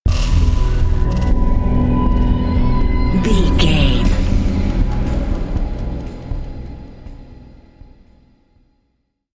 Aeolian/Minor
WHAT’S THE TEMPO OF THE CLIP?
piano
synthesiser
percussion